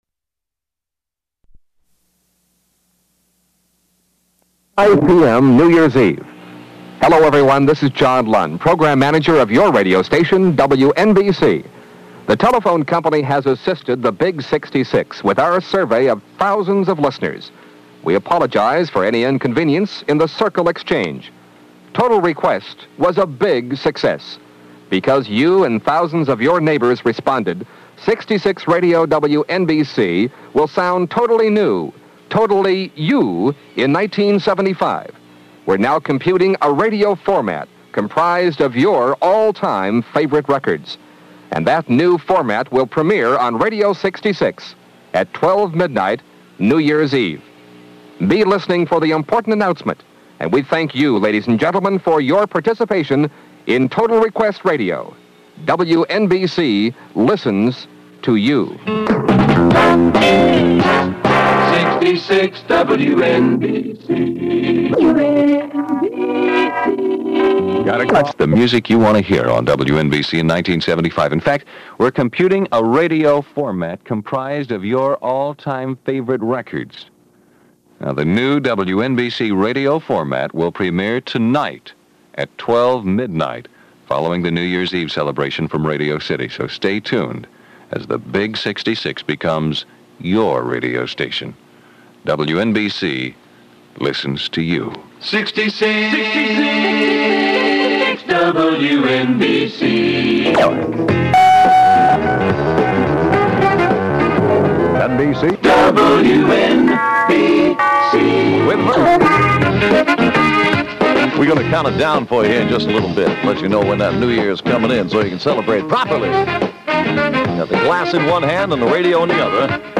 The Archives Dealer's Choice (6.5 MB) So what on earth am I doing with an audio recording of an entire episode of Dealer's Choice? Back in 1975, I watched this television game show religiously every night.
I placed my cassette recorder in front of a big Magnavox TV set and used a mechanical timer from Radio Shack to trip the tape recorder close to 8:00 PM so that I could time-shift the program and listen to it after I returned home. The basement had little furniture and a green, vinyl tile floor, and the TV had no audio jack, so that's why the sound has a bit of an echo to it.